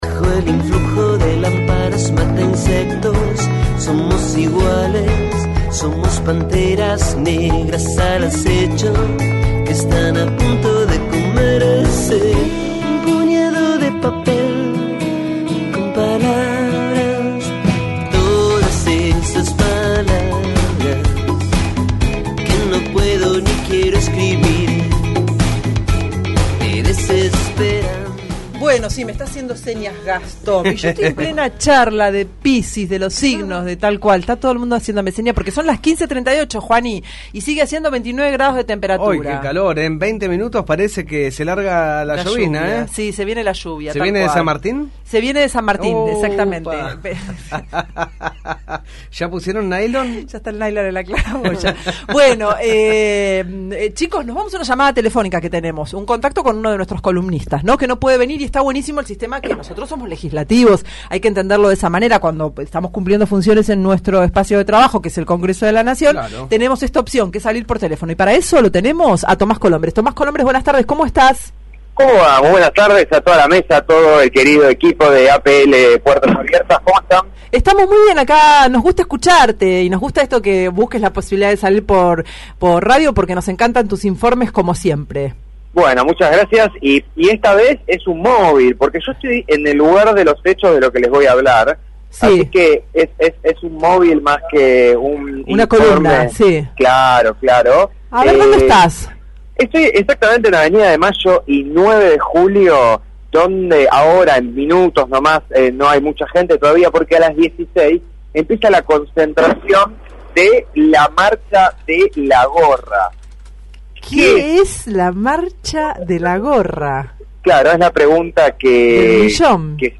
Movil desde la Marcha de La Gorrra